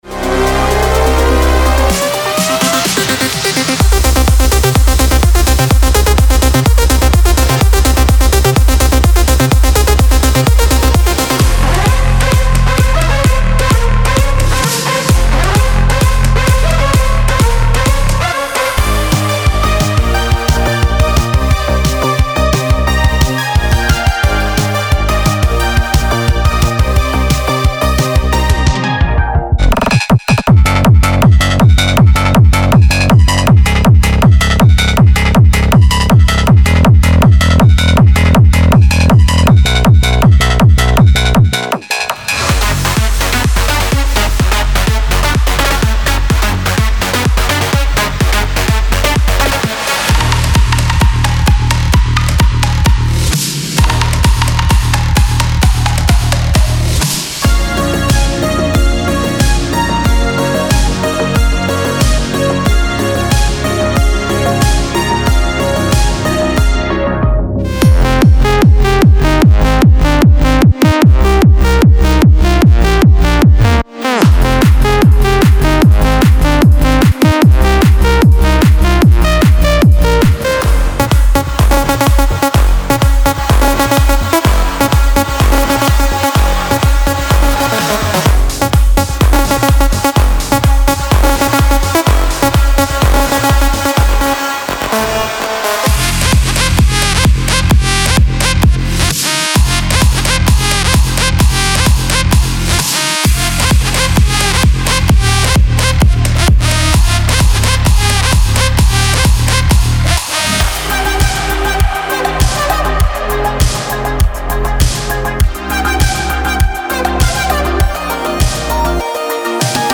Big Room Future Rave Synthwave / Retrowave Techno